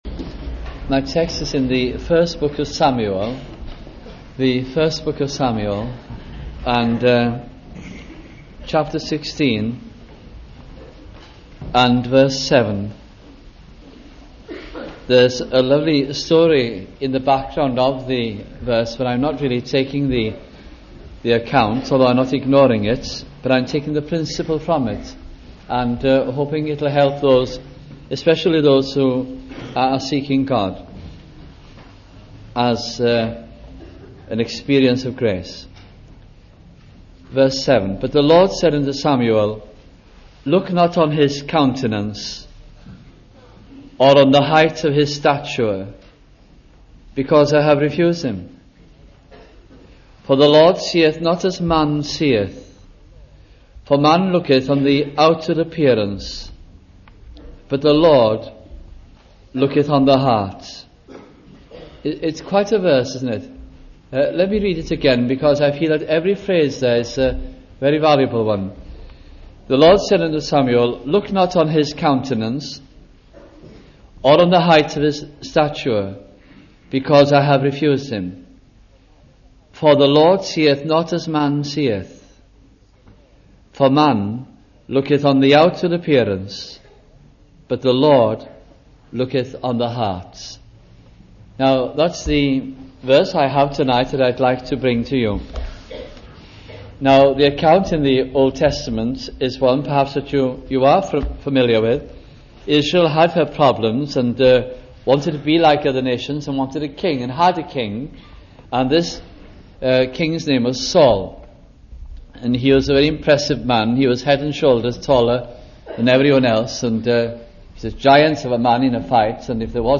» 1 Samuel Gospel Sermons